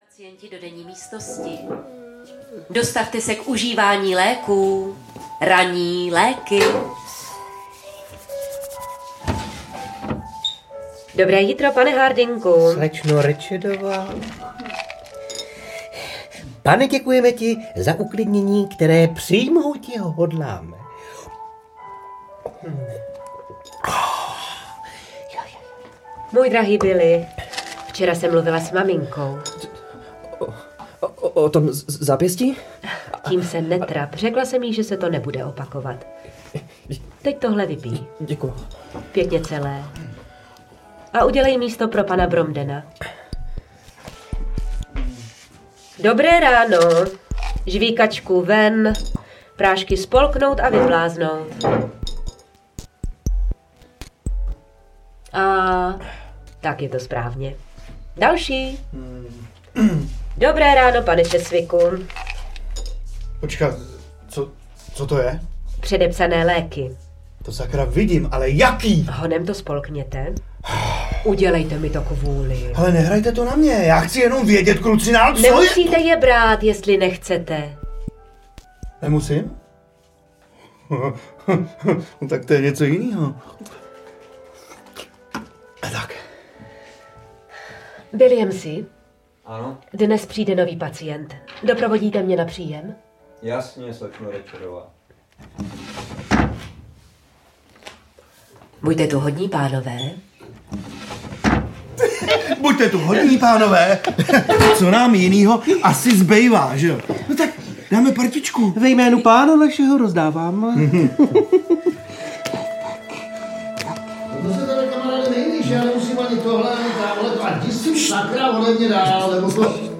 Dětské hlasy členů Dismanova rozhlasového dětského souboru nepříjemně útočí na emoce posluchačů a vzbuzují pocit, že před dobře propracovanou a zažitou mašinerií nelze utéct…